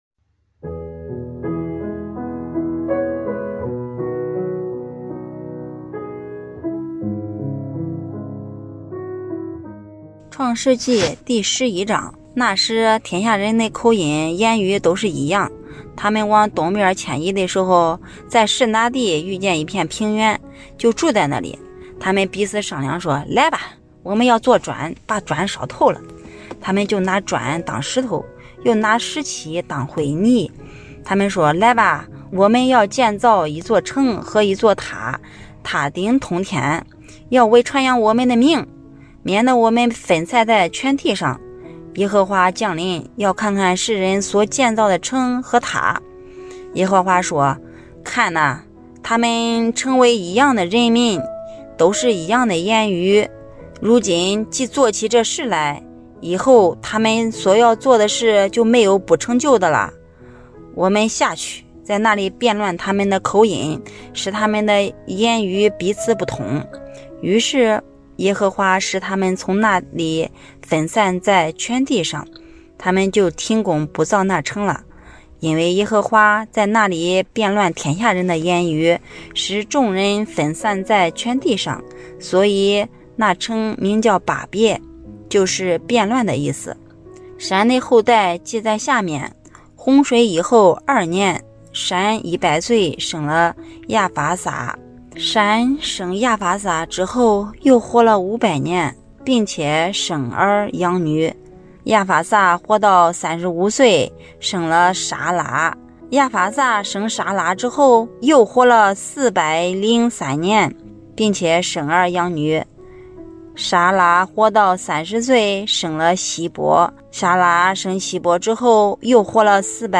读经马拉松 | 创世记11章(河南话)
语言：河南话